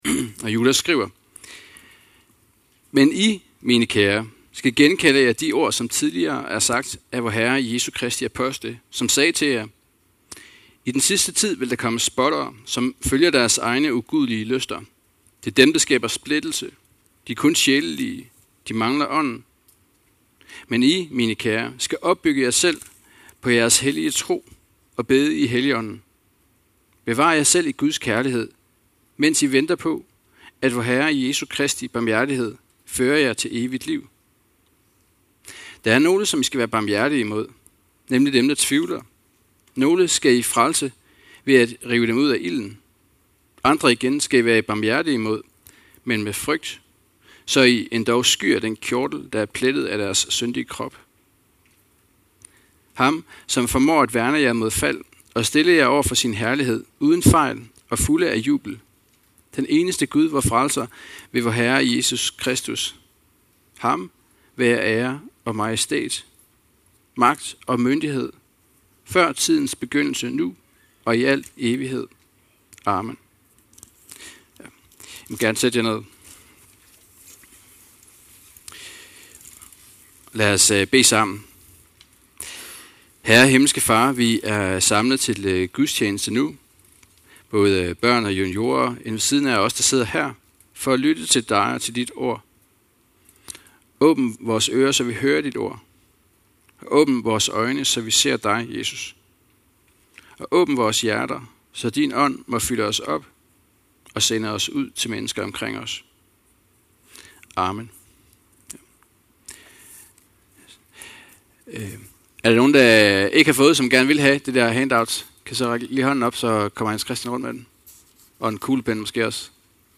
Byg jer op i troen (Judas brev v17-25) – Undervisning